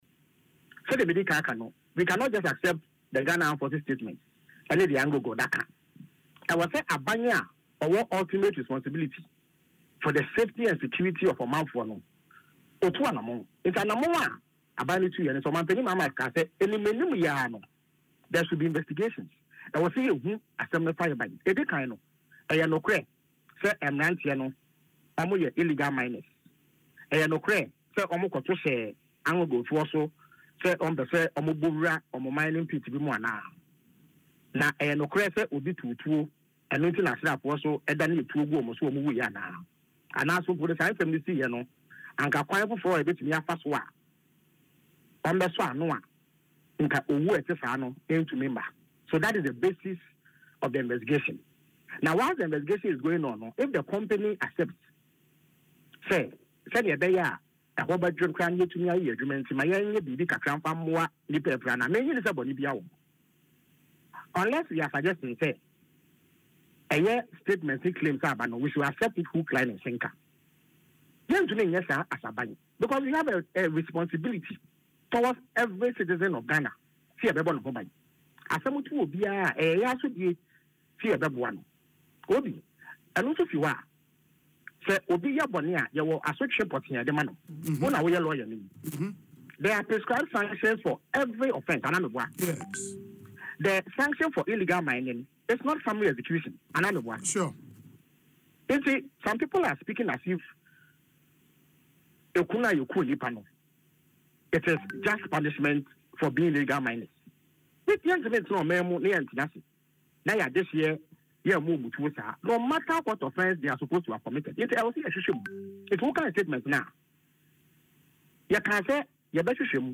Speaking on Asempa FM’s Ekosii Sen show, the Abura Asebu Kwamankese MP stated that the probe is necessary to gain a clear perspective on the incident and ensure peace and order.